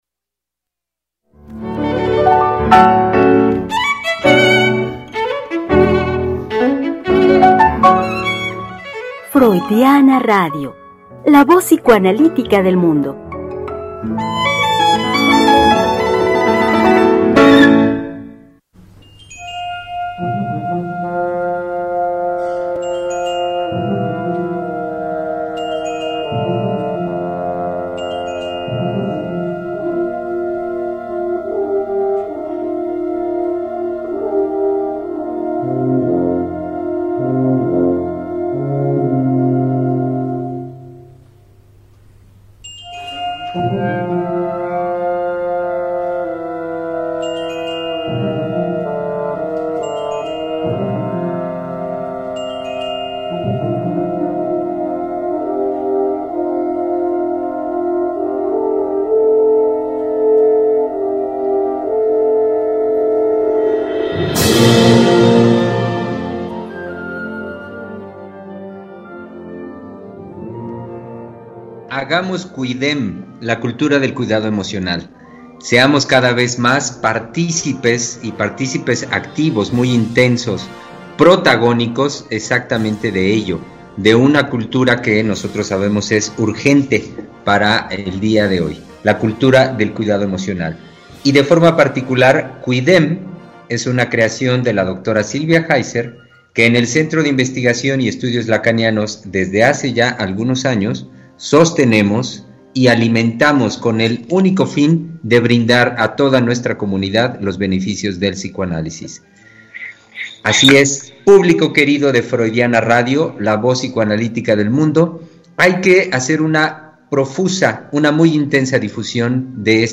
Conversación con nuestros invitados los psicoanalistas del CIEL.